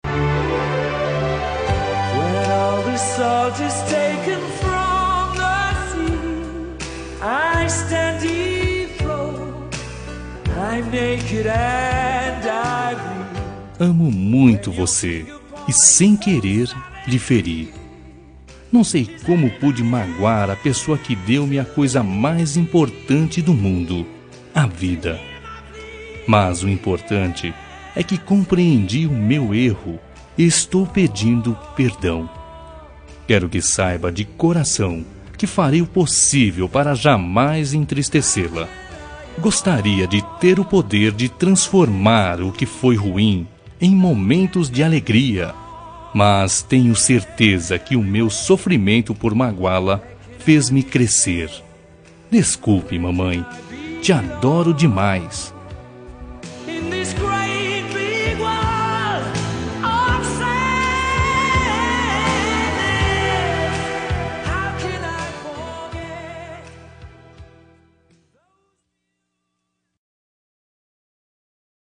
Reconciliação Familiar – Voz Masculina – Cód: 088733 – Mãe